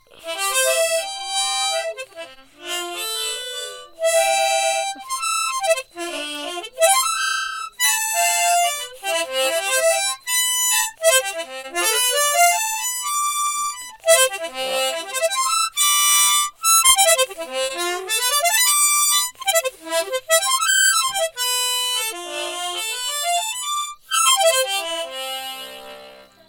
하모니카.mp3